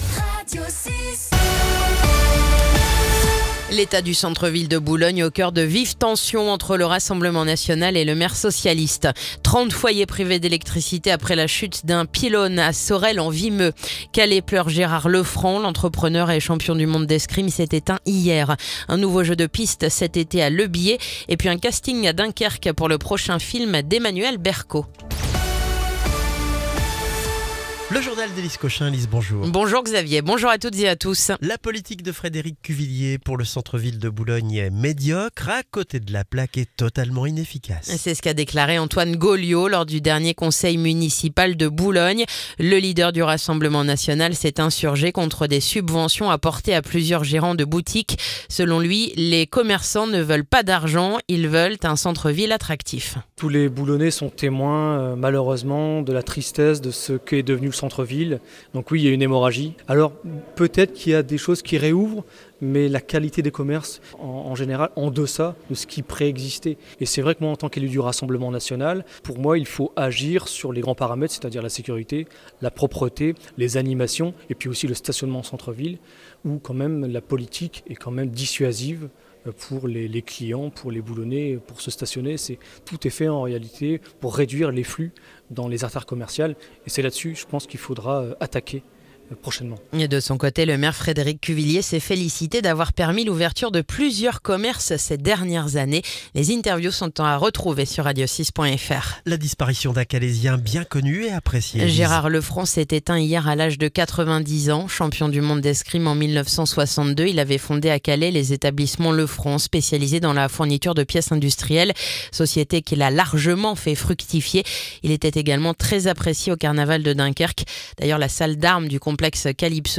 Le journal du mardi 24 juin